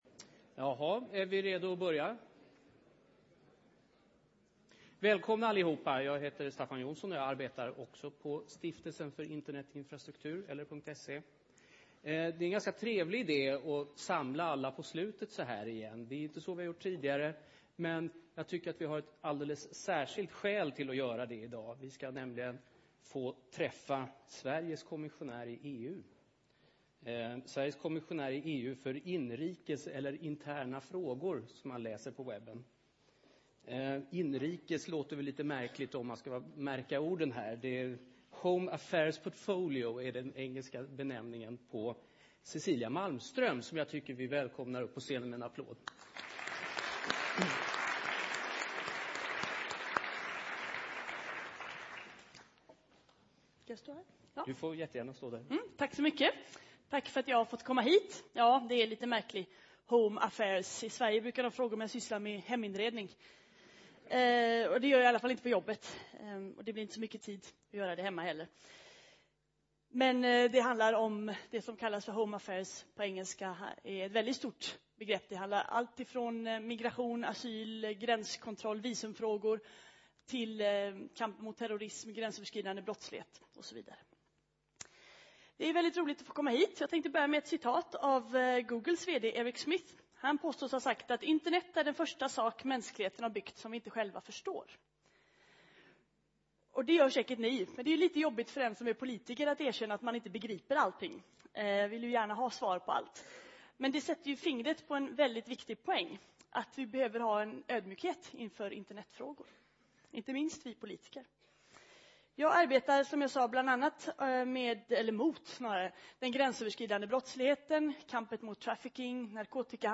Internet - möjligheter och hot (LIVESÄNDS) Plats: Kongresshall A Datum: 2010-10-26 Tid: 17:15-17:45 Lyssna på seminariet Talare: Cecilia Malmström EU-kommissionen Internet - möjligheter och hot Internet innebär fantastiska möjligheter, men också nya hot som vi måste möta.
keynote-tis-em.mp3